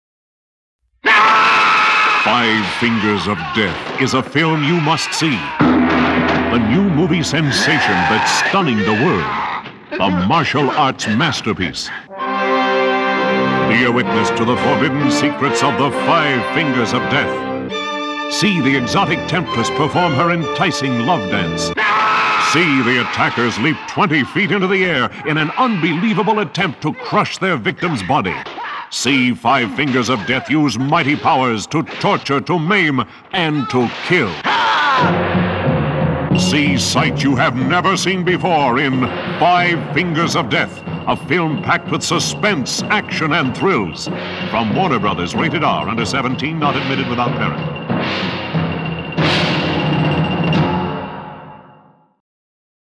Radio Spots
So now, though, put on your gi or your Kung Fu uniform, assume your beginning stance, and listen to radio spots for the movie that started it all.